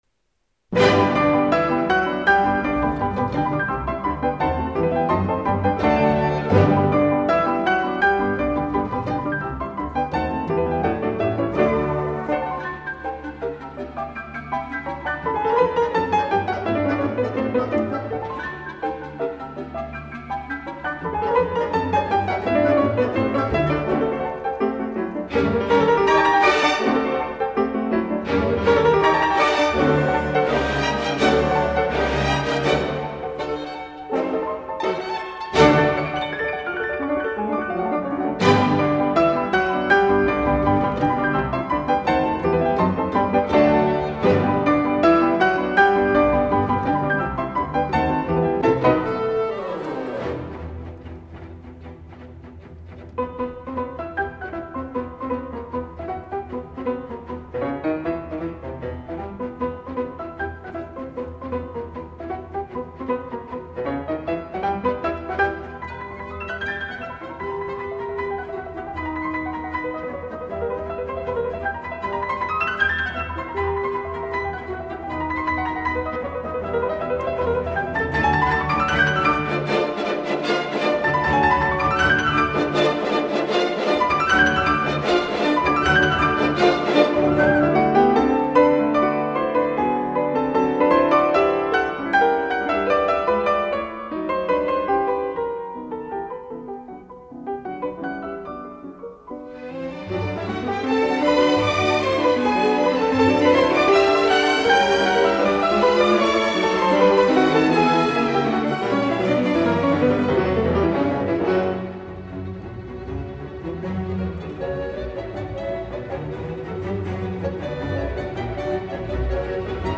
соль мажор